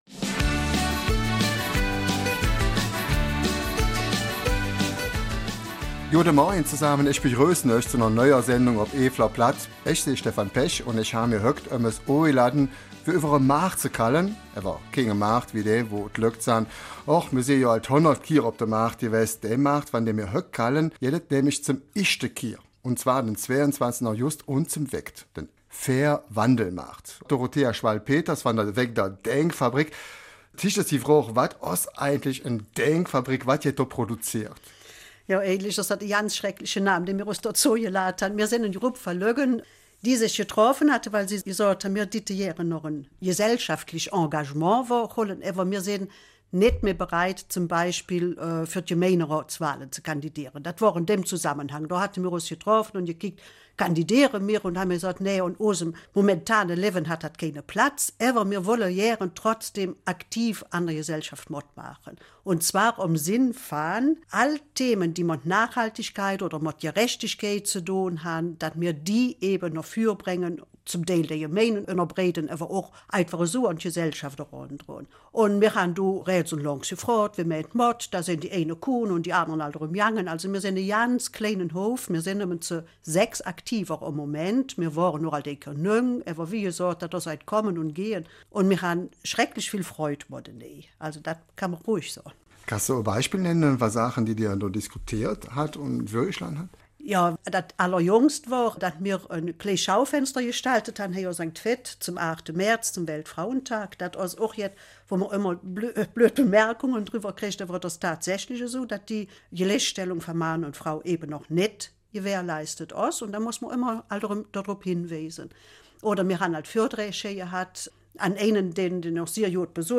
Eifeler Mundart: ''Fair-Wandel-Markt'': es geht auch anders!